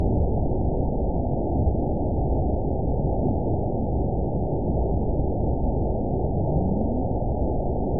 event 912493 date 03/27/22 time 21:33:48 GMT (3 years, 1 month ago) score 8.89 location TSS-AB04 detected by nrw target species NRW annotations +NRW Spectrogram: Frequency (kHz) vs. Time (s) audio not available .wav